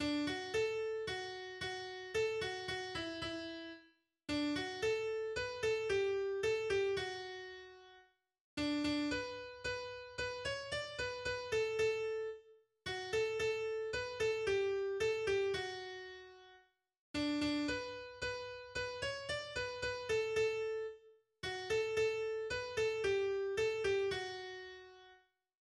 Heimatlied aus Schlesien.
Das melancholisch anmutende Lied Hohe Tannen weisen die Sterne, das auch als »Rübezahllied« bezeichnet wird, ist ein altes schlesisches Volkslied aus dem 18. Jahrhundert nach dessen Melodie auch das fränkische Lied gesungen wird. Es besingt die sagenumwobene Gestalt des gutmütigen Bergriesen »Rübezahl«, der als Beschützer gepriesen wird.